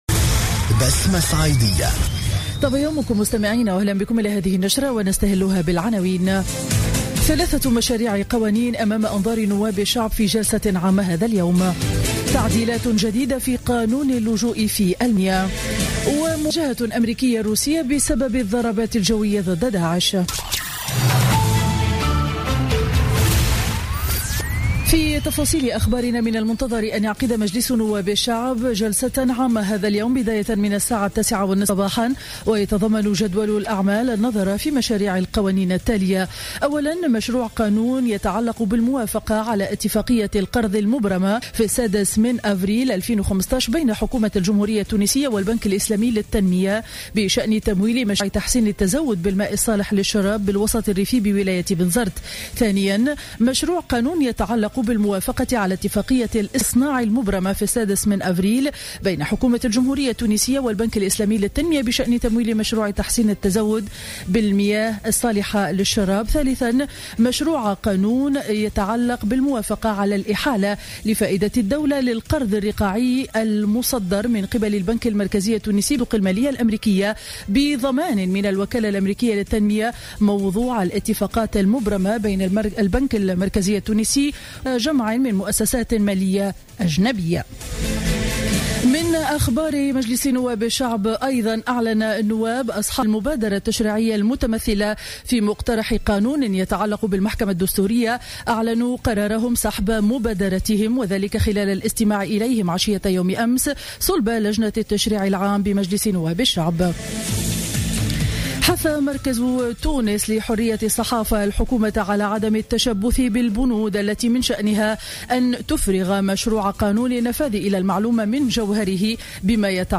نشرة أخبار السابعة صباحا ليوم الخميس غرة أكتوبر 2015